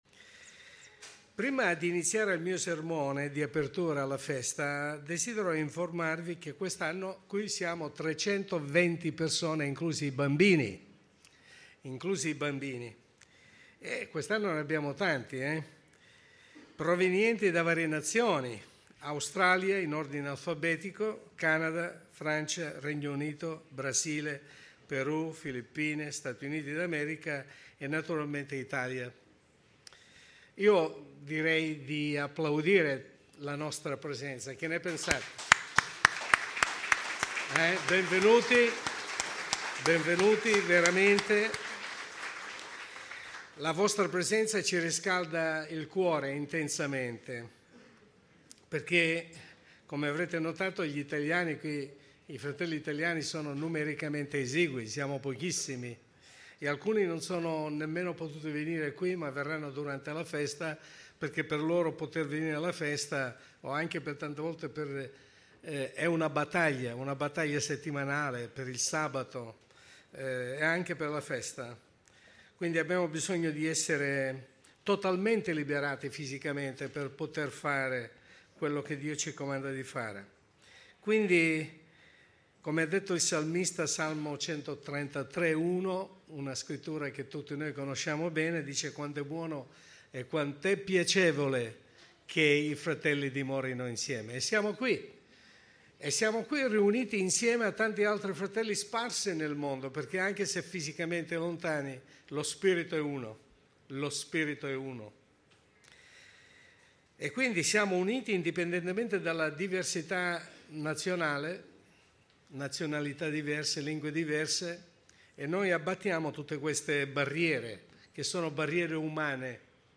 Sermone